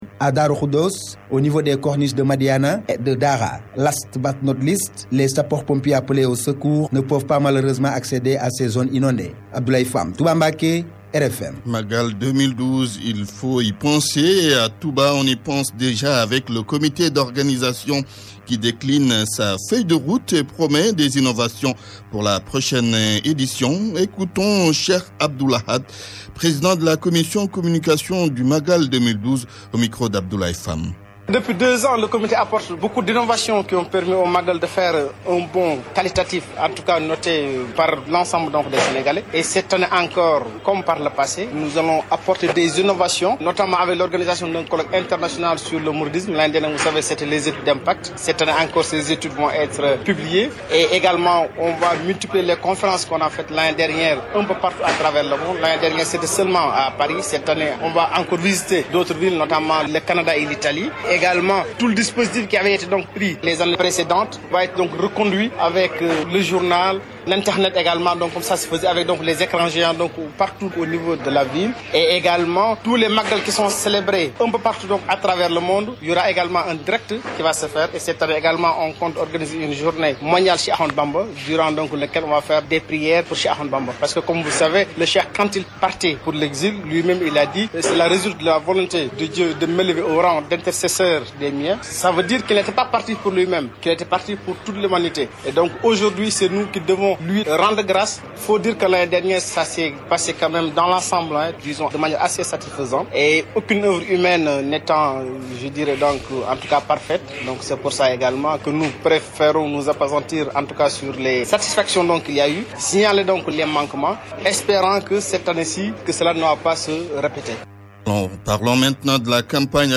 [ AUDIO ] Le journal parlé de la RFM du 30 Septembre ( 12 h - Wolof )